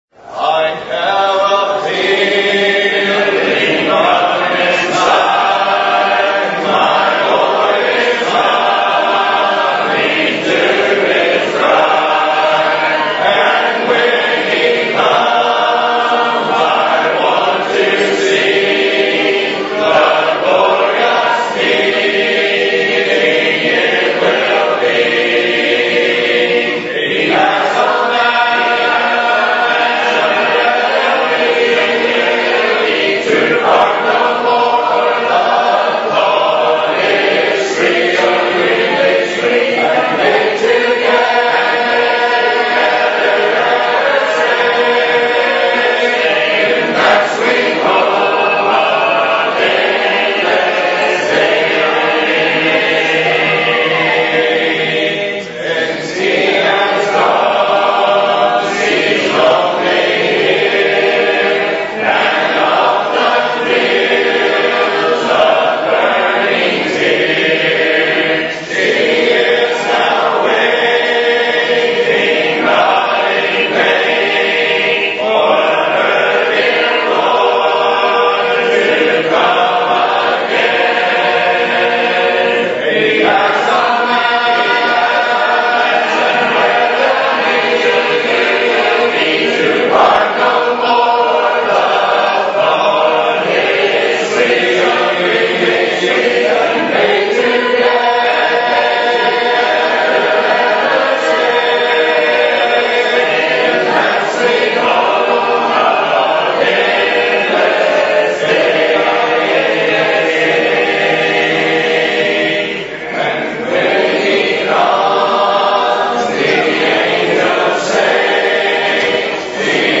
Part (A) Congregational Singing at 2018 Ebenezer Fellowship Meeting hosted by Trail Branch PBC {Rev}
Service Type: Singing
CongregationalSinging_3dreverb_at2018EbenezerFellowshipMeetingHostedByTrailBranchPBC.mp3